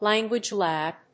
アクセントlánguage làb